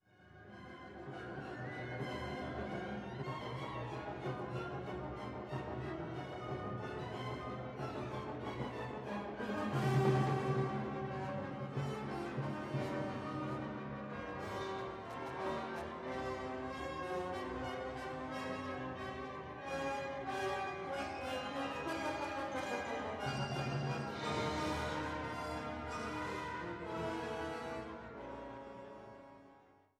Konzert für 3 Fagotte, Kontrafagott und Orchester op. 51